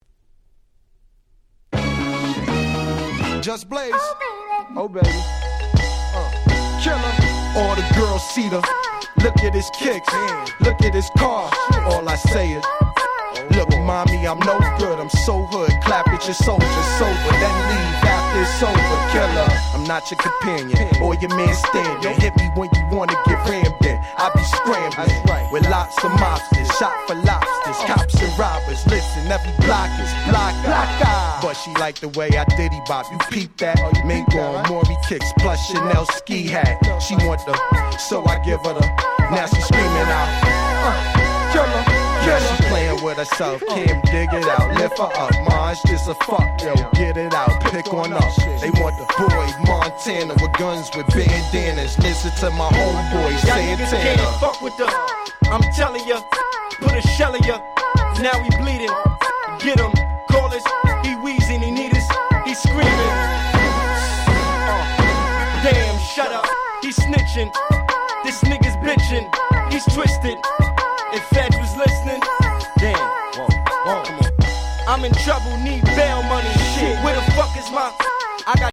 02' Super Hit Hip Hop !!
Soul早回しネタ系Hip Hopの最高峰。